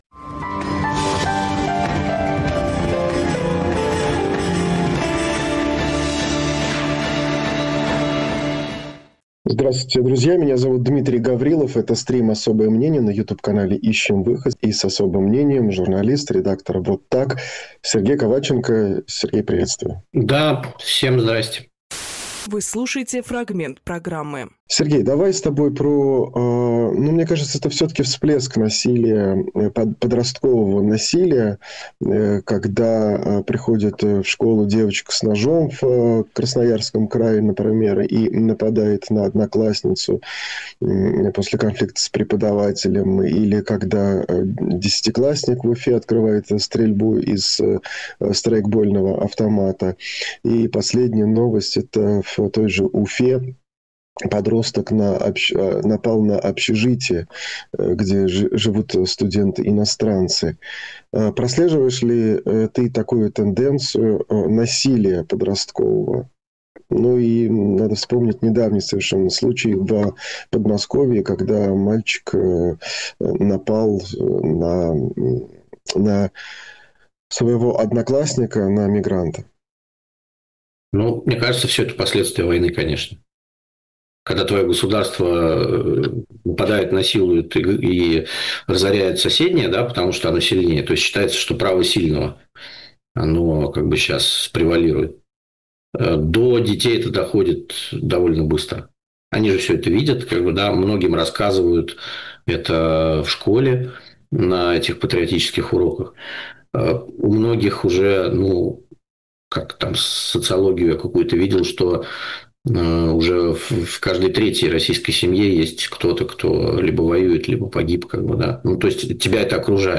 Фрагмент эфира от 09.02.26